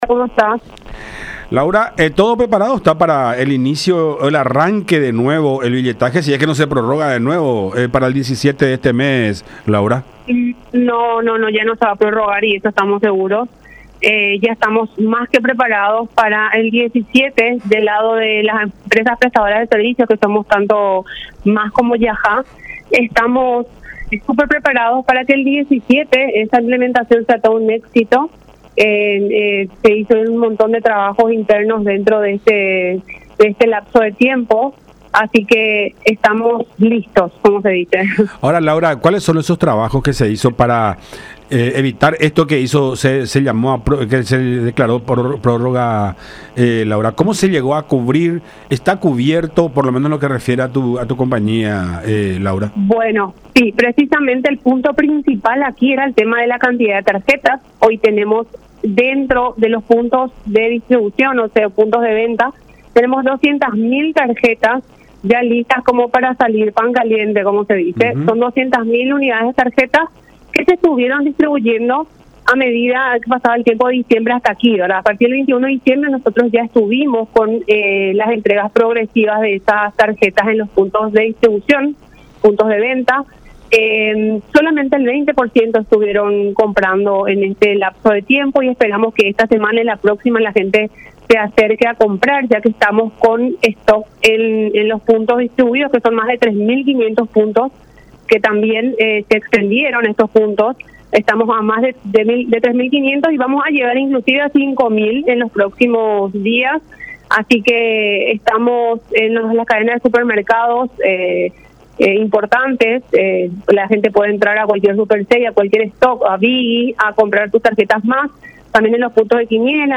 en contacto con La Unión R800 AM.